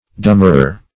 Dummerer \Dum"mer*er\